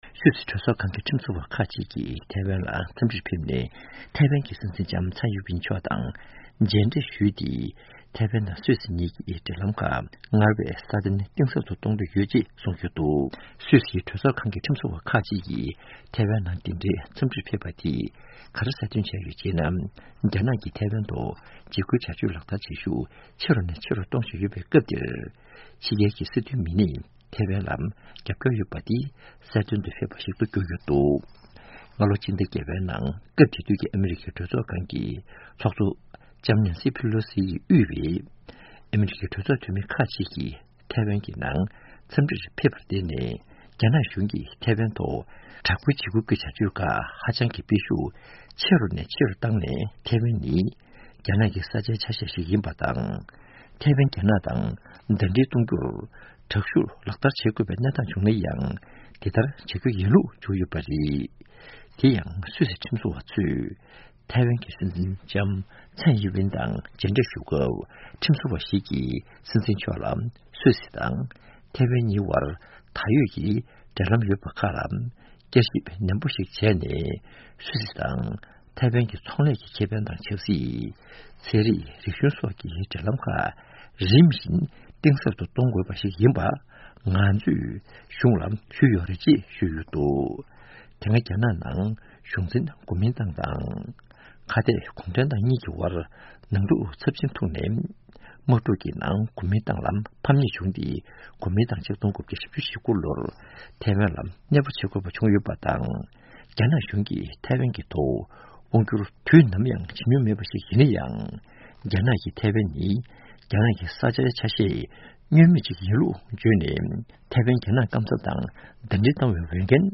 སྙན་སྒྲོན་གནང་བ་ཞིག་འདིར་གསལ།